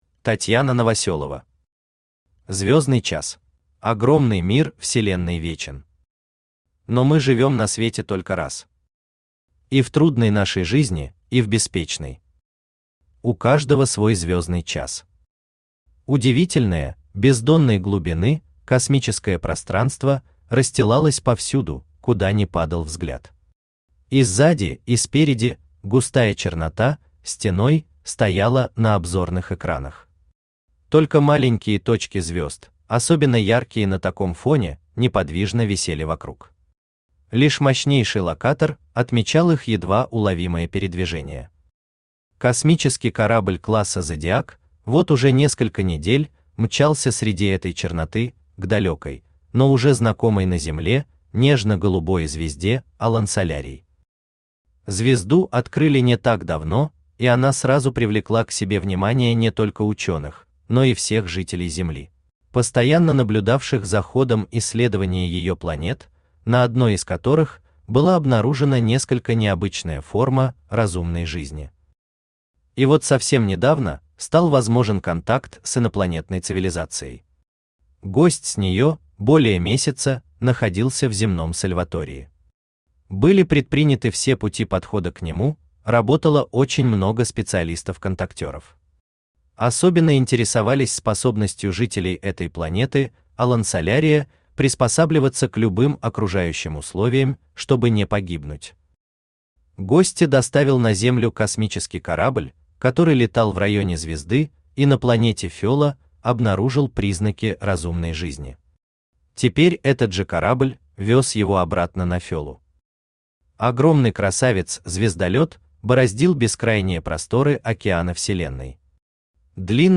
Aудиокнига Звёздный час Автор Татьяна Михайловна Новосёлова Читает аудиокнигу Авточтец ЛитРес.